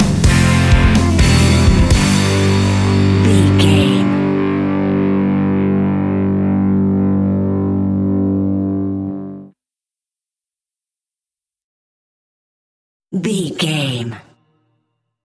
Ionian/Major
energetic
driving
aggressive
electric guitar
bass guitar
drums
hard rock
heavy drums
distorted guitars
hammond organ